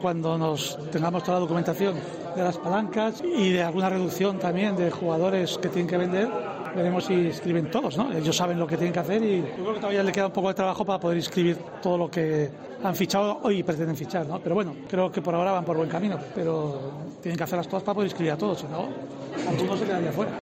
Yo creo que les queda un poco de trabajo para todo lo que tienen que hacer, pero creo que van por buen camino”, dijo antes del inicio de la Gala ‘Kick Off’ de la temporada 2022/23.